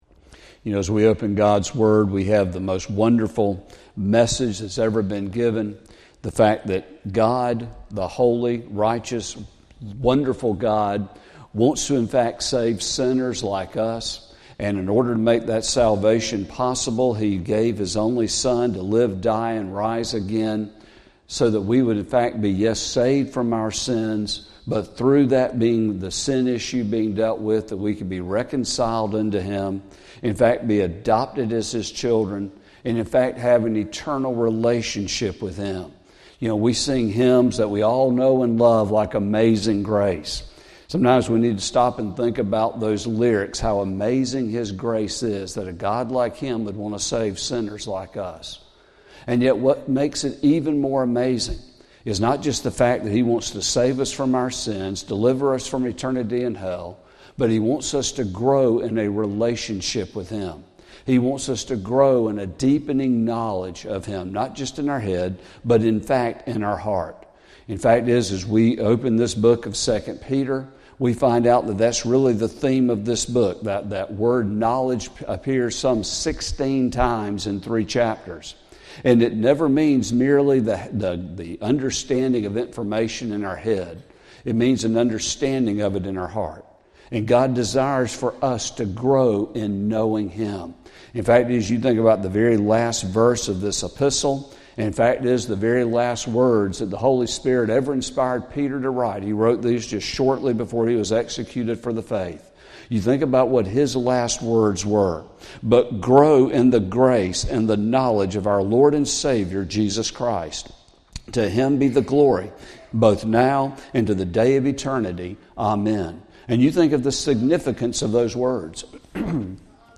Sermon | February 9, 2025